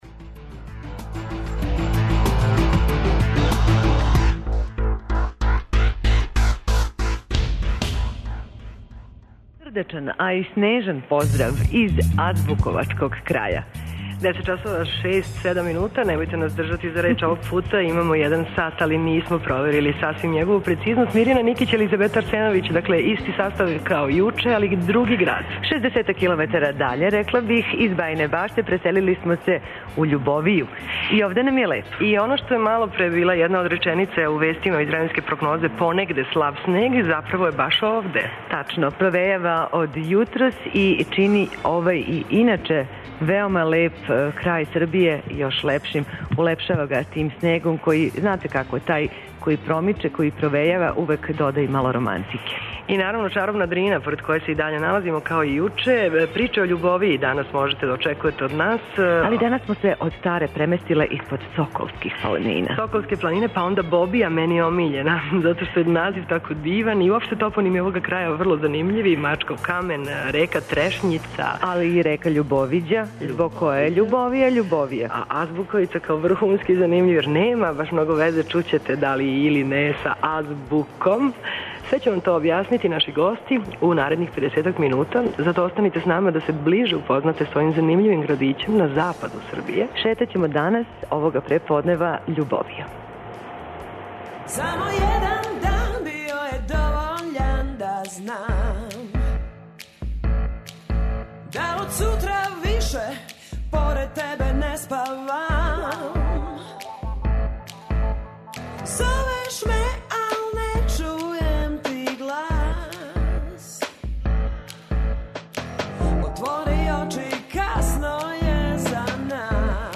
Данас шетамо Љубовијом. Емисију емитујемо уживо из овог града, упознаћемо вас са његовим житељима, предивном природом која га окружује, културним добрима...